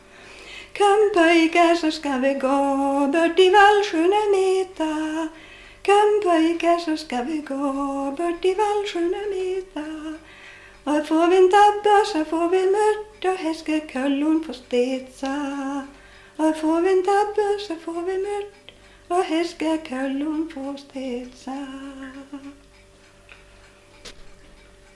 Vispolska, fiolmelodi och hornlåt